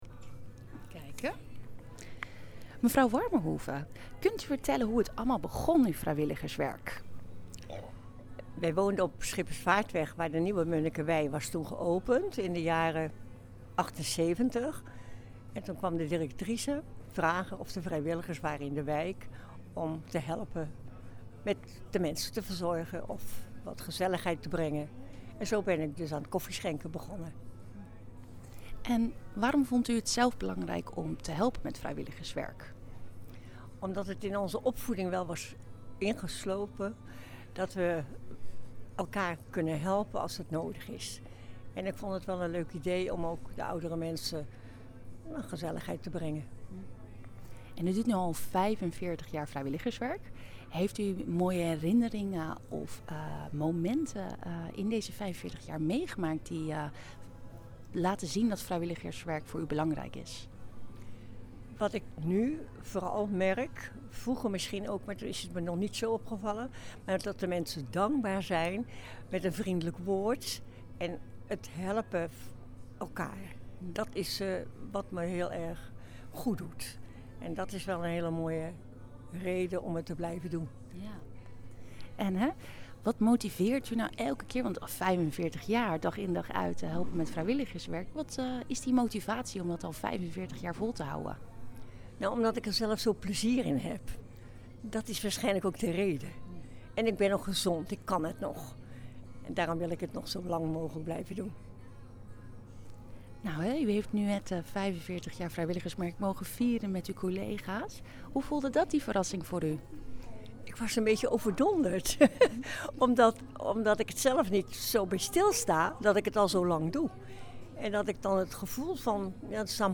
ging langs bij de viering